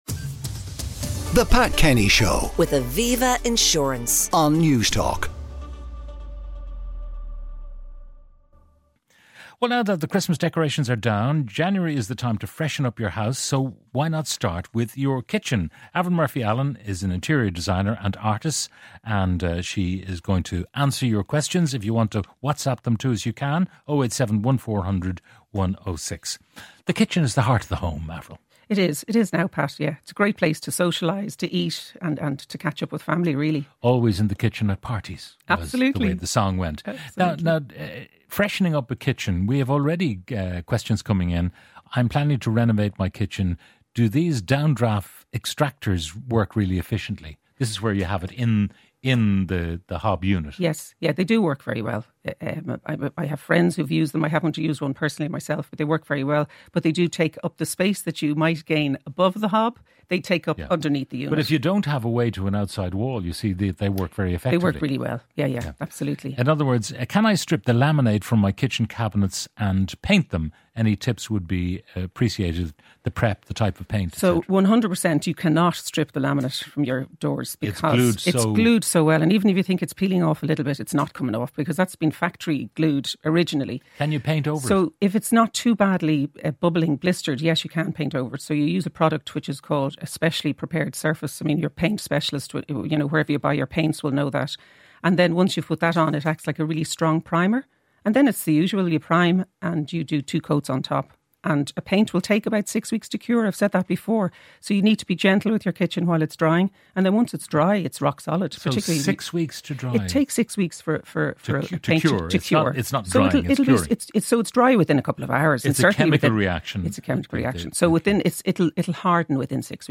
Pat brings you the sharpest analysis of news and current affairs on the radio and fresh perspectives on the issues that will define a generation. Breaking news is interwoven with reflective news features and reports from a variety of reporters based across the country.